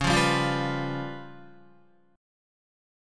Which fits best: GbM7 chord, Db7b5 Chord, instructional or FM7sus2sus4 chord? Db7b5 Chord